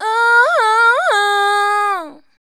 SUFFERING.wav